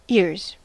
Ääntäminen
Ääntäminen US UK GenAm: IPA : /ɪɹz/ RP : IPA : /ɪəz/ Haettu sana löytyi näillä lähdekielillä: englanti Käännöksiä ei löytynyt valitulle kohdekielelle. Ears on sanan ear monikko.